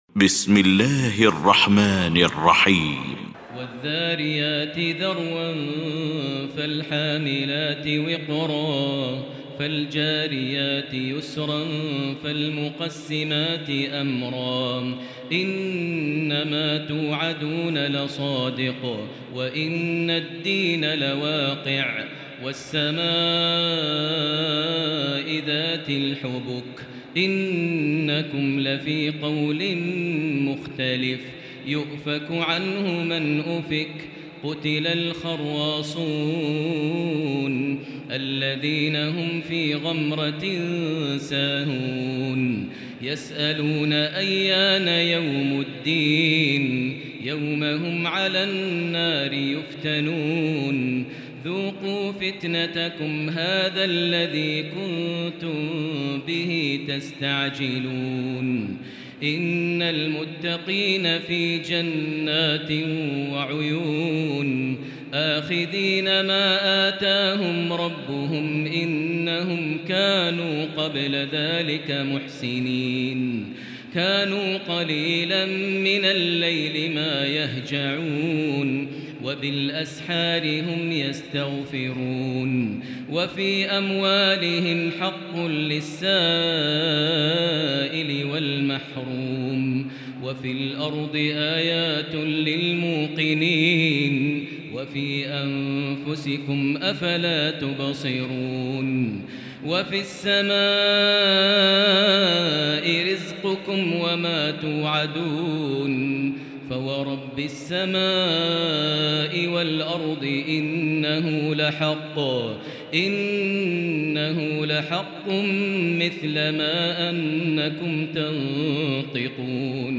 المكان: المسجد الحرام الشيخ: فضيلة الشيخ ماهر المعيقلي فضيلة الشيخ ماهر المعيقلي الذاريات